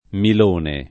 [ mil 1 ne ]